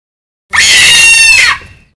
Baboon
Category 🐾 Animals
ape baboon cry monkey screech zoo sound effect free sound royalty free Animals